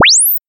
正弦扫频" 0赫兹至22500k赫兹，时间为0.5秒
描述：在Audacity产生的正弦波扫描从0赫兹到22.5千赫兹。